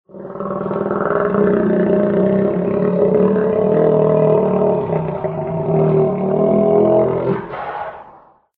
Buaya_Suara.ogg